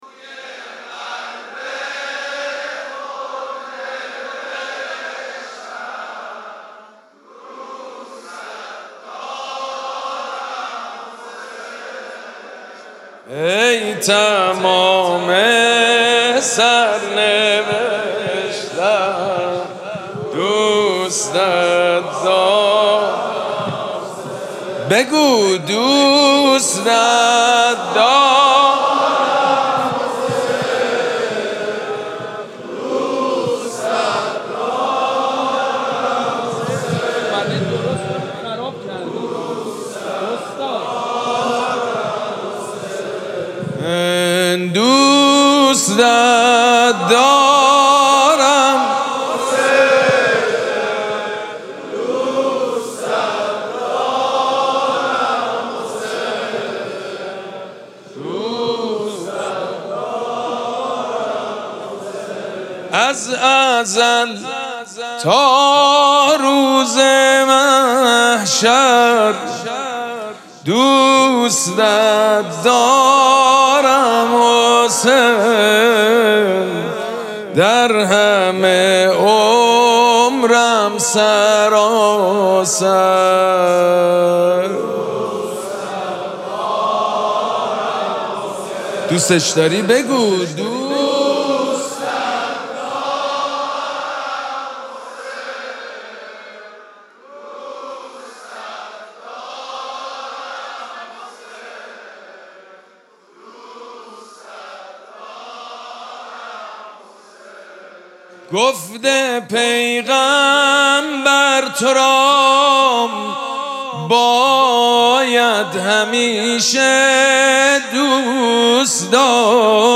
شب اول مراسم جشن ولادت سرداران کربلا
حسینیه ریحانه الحسین سلام الله علیها
شعر خوانی
حاج سید مجید بنی فاطمه